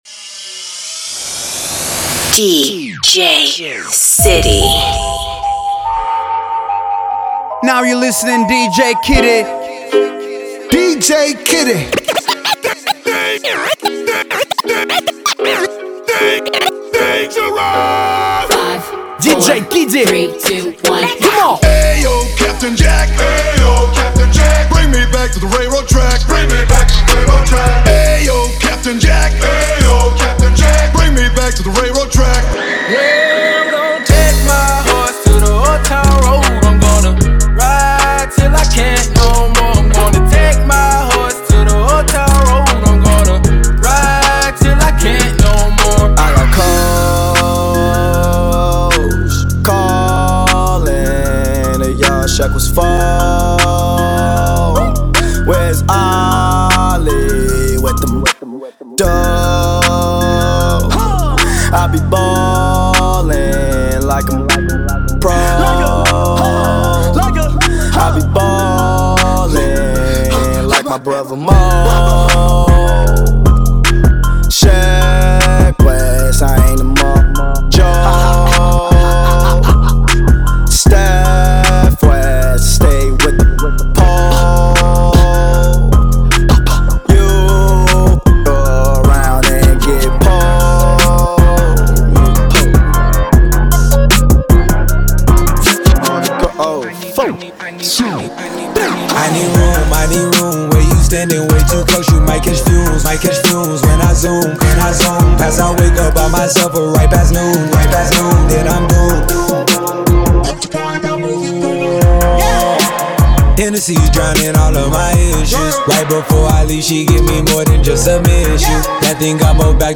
hip-hop
очень душевно и танцевально получилось!